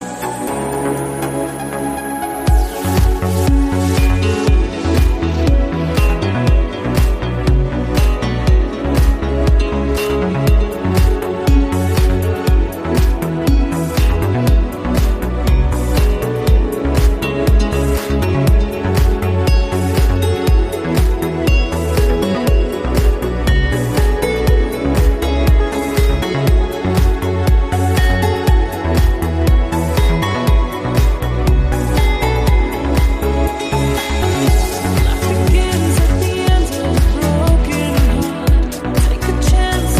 • Качество: 128, Stereo
deep house
мелодичные